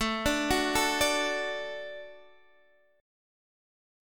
Gbm#5/A chord